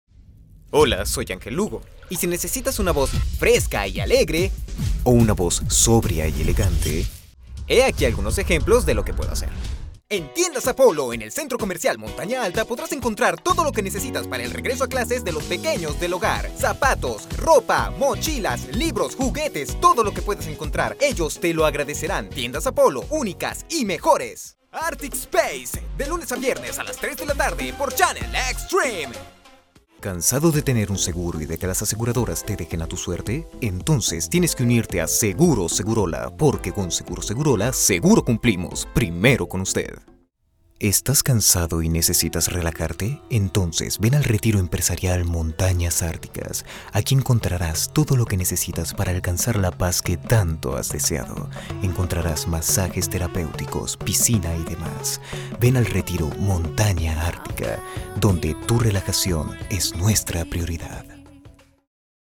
DEMO DOBLAJE MASCULINO
Demo Doblaje Profesional
Latin neutral Spanish